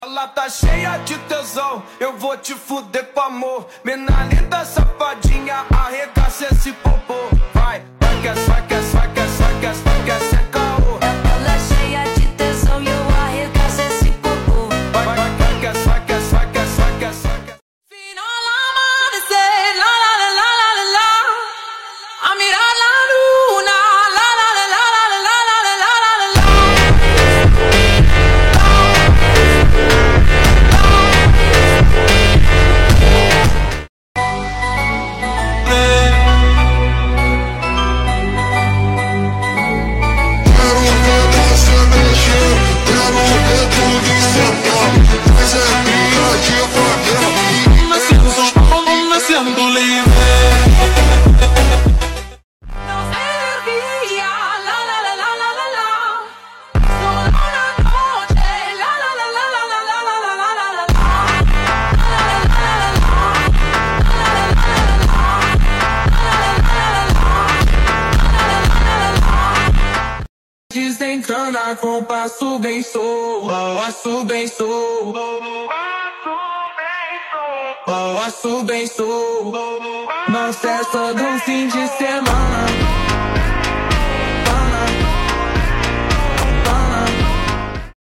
phonk/funk songs